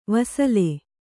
♪ vasale